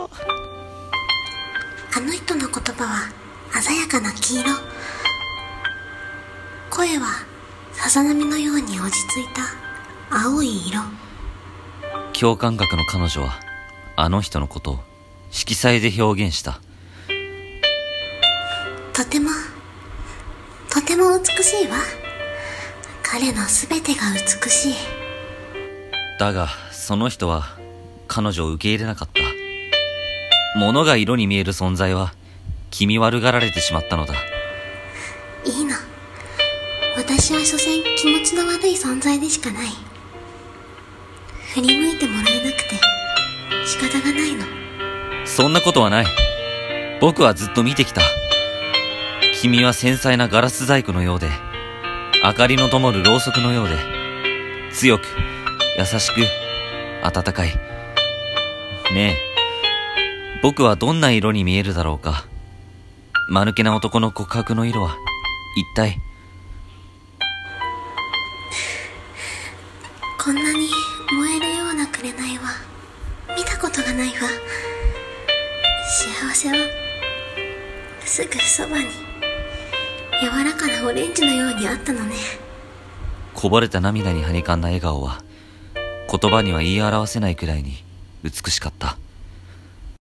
【二人声劇】しあわせのなまえ